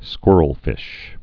(skwûrəl-fĭsh, skwŭr-)